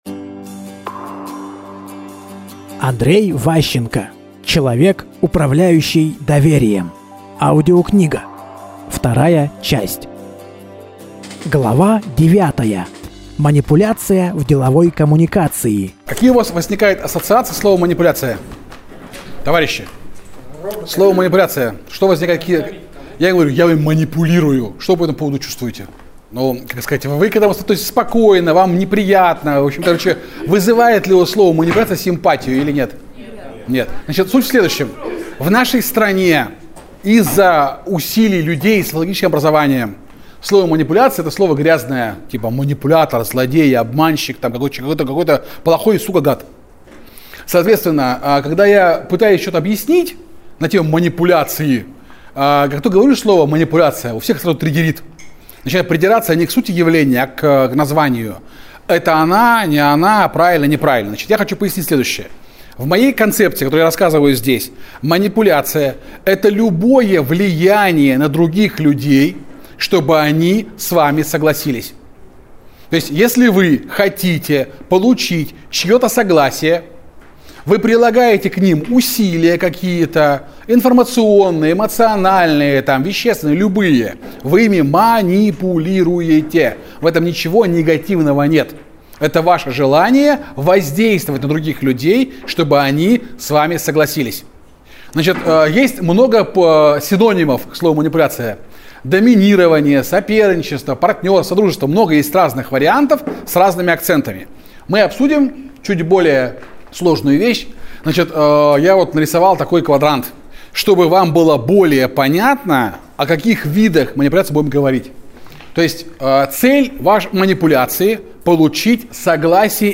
Аудиокнига Человек, управляющий доверием. Часть 2 | Библиотека аудиокниг